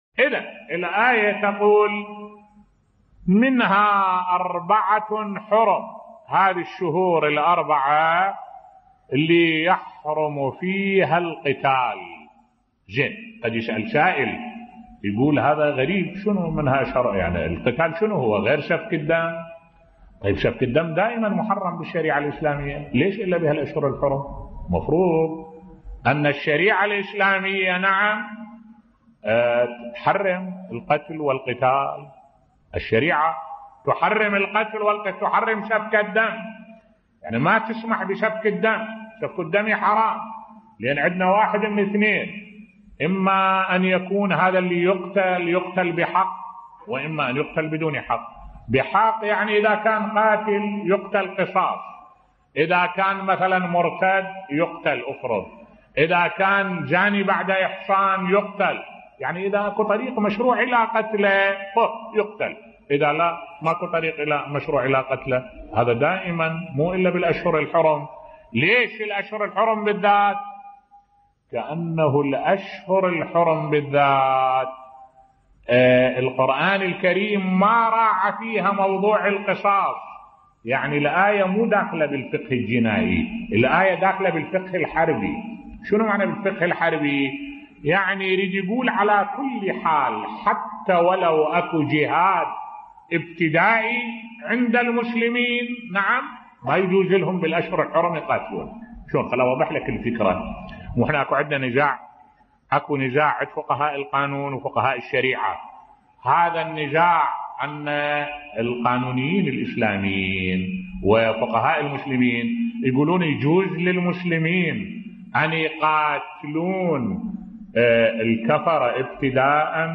ملف صوتی الجهاد الابتدائي في الاسلام بصوت الشيخ الدكتور أحمد الوائلي